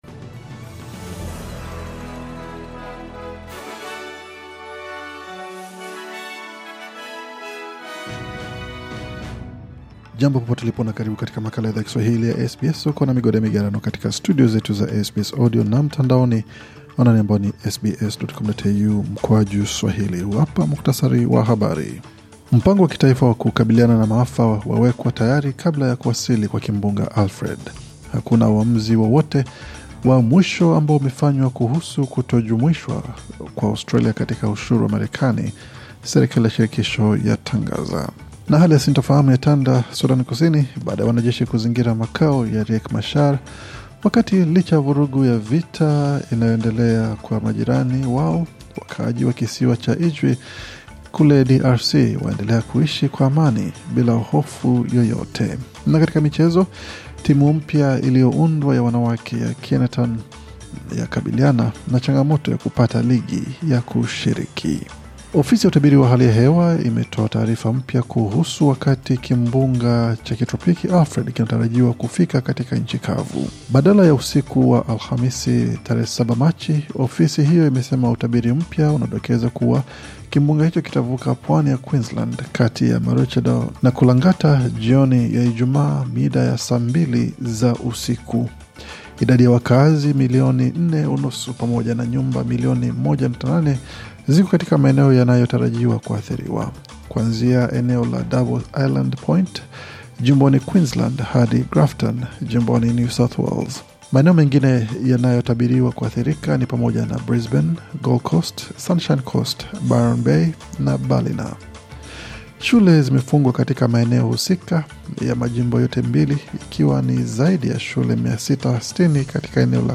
Taarifa ya Habari 6 Machi 2025